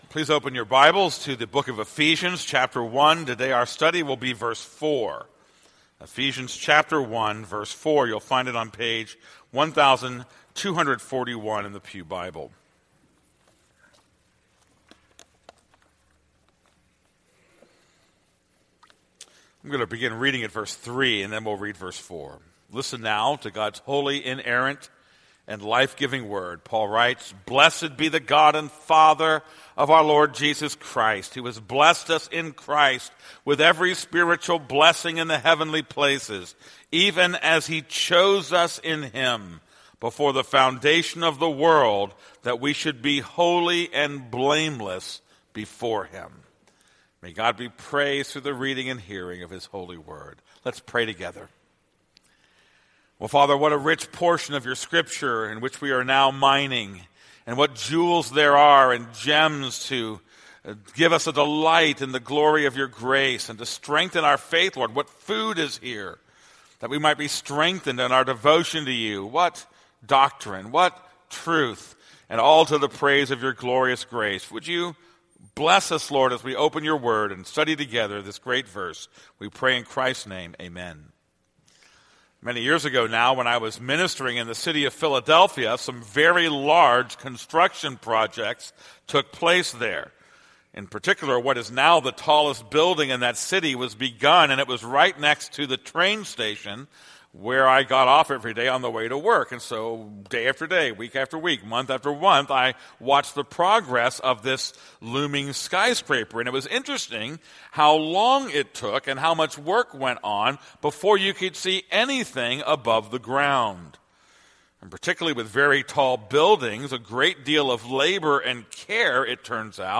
This is a sermon on Ephesians 1:4.